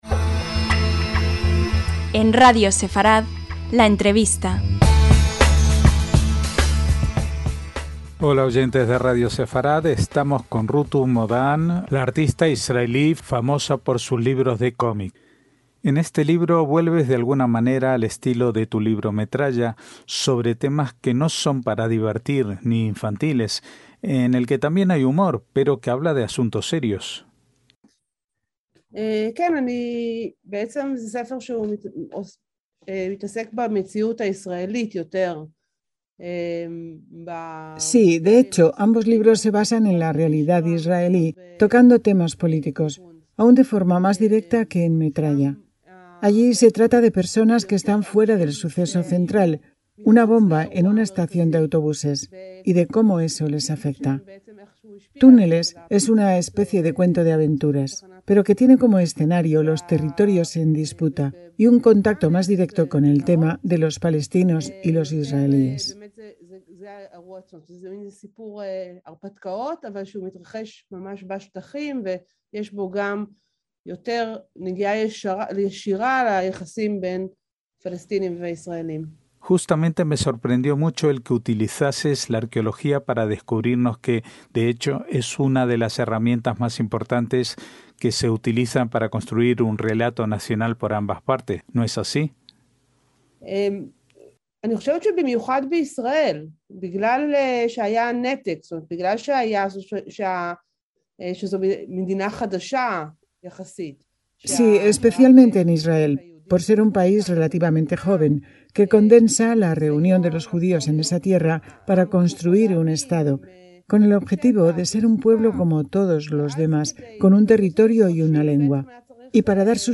LA ENTREVISTA - Radio Sefarad ha hablado en exclusiva y profundidad con Rutu Modan, la autora israelí de novelas gráficas tan famosas como "Metralla" con ocasión de la presentación de su nuevo título en español, "Túneles" en el que retrata la realidad de Israel y Medio Oriente como nadie lo ha conseguido hacer hasta ahora en ese terreno.